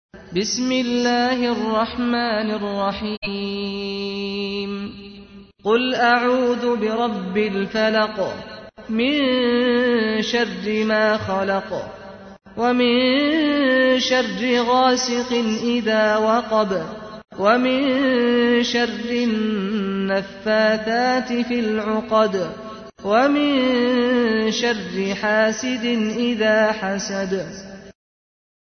تحميل : 113. سورة الفلق / القارئ سعد الغامدي / القرآن الكريم / موقع يا حسين